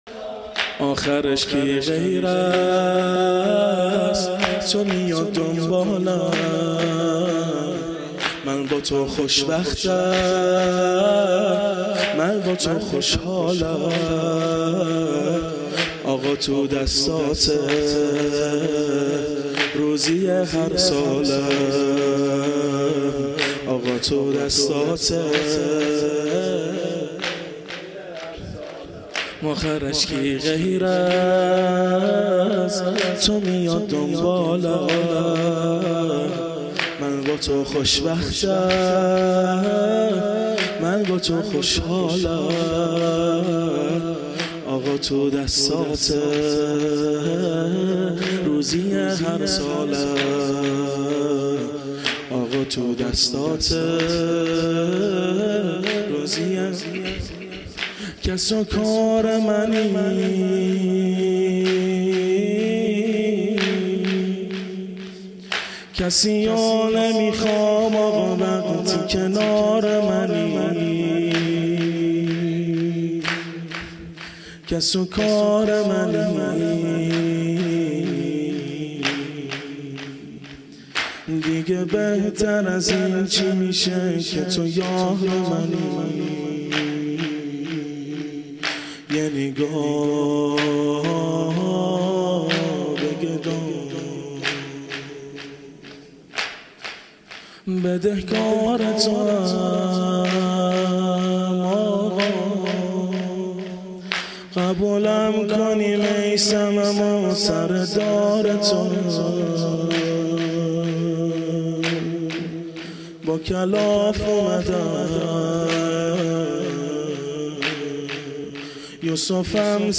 مداحی
هیأت علی اکبر بحرین